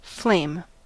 Ääntäminen
IPA : /ˈfleɪm/ US : IPA : [ˈfleɪm]